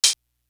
Sharp Delta Hat.wav